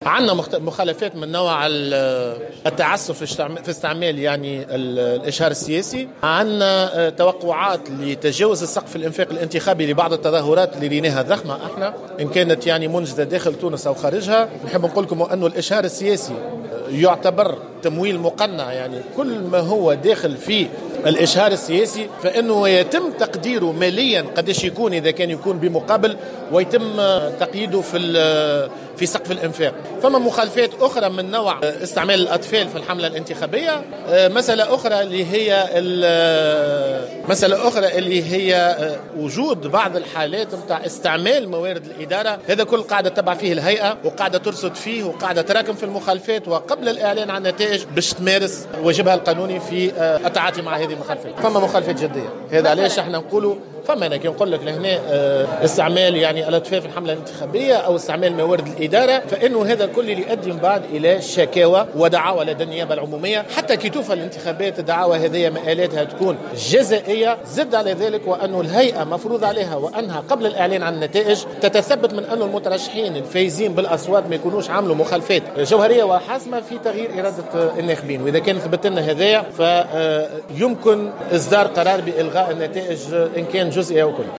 قال رئيس الهيئة العليا المستقلة للانتخابات نبيل بفون، اليوم الخميس بمناسبة افتتاح المركز الإعلامي للهيئة بقصر المؤتمرات بالعاصمة، إن المخالفات التي وقع تسجيلها في الأيام الأخيرة من حملة الانتخابات الرئاسية السابقة لأوانها كانت خطيرة، مؤكدا أن تدخل الهيئة سيكون بالتنبيه على الفاعلين الانتخابيين كلما لاحظت تجاوزات غير مقبولة.